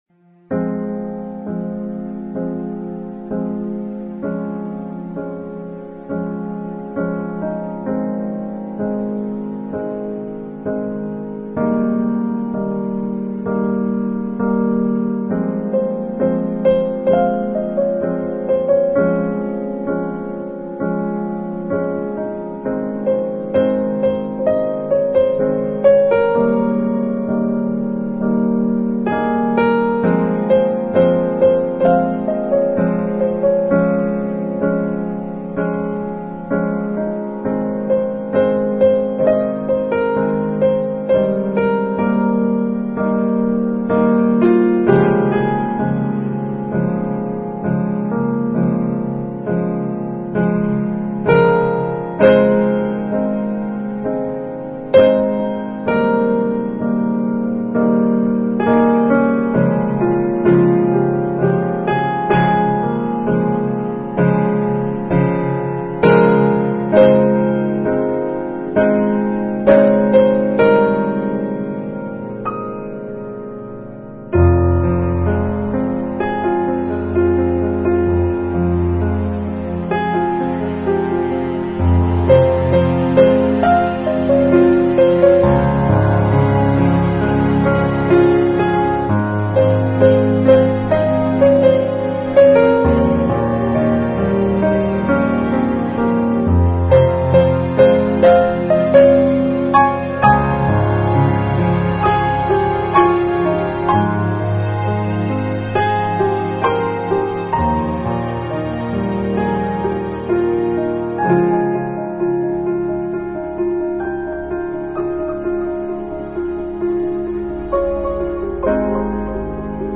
Piano Sad Love